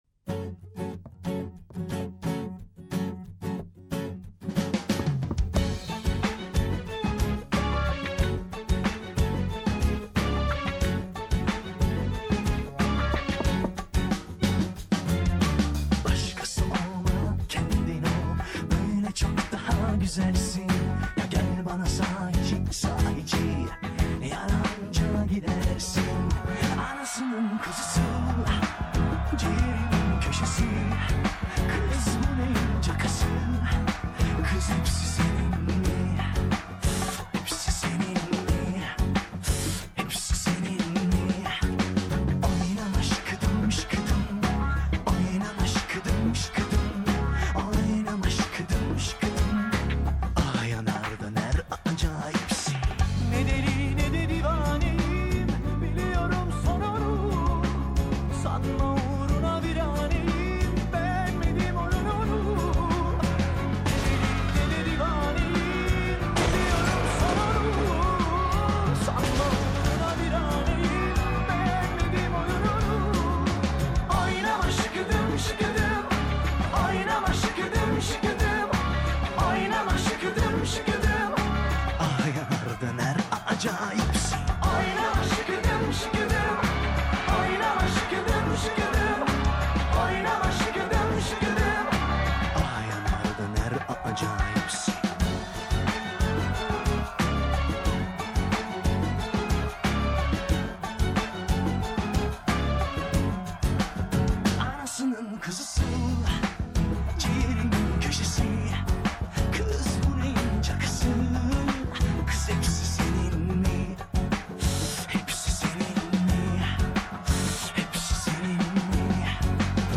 Turkish Pop, Electronic